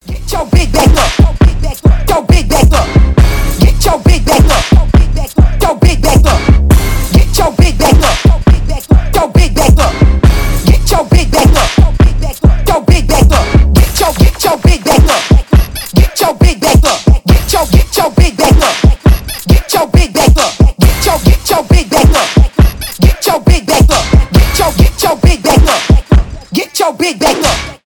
ритмичные , рэп